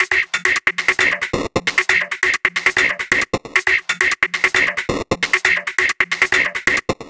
Session 04 - Percussion.wav